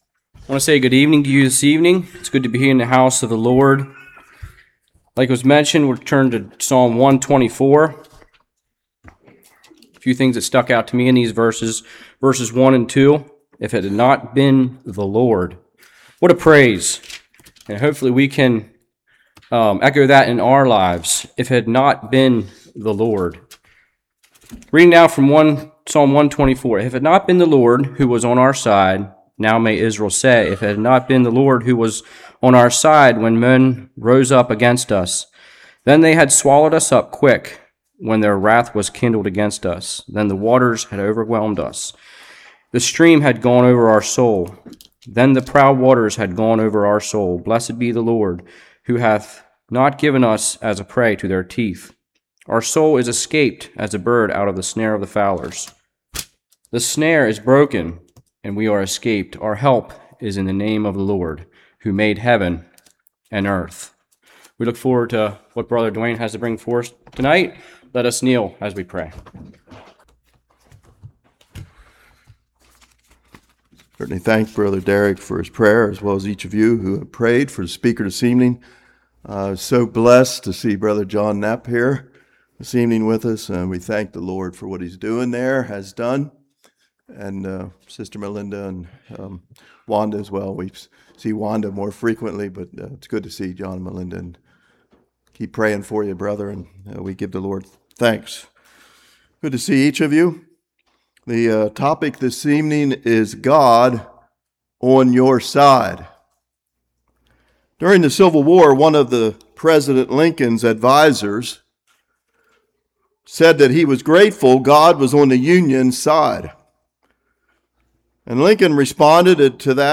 Psalm 124 Service Type: Evening If it had not been for the Lord on our side.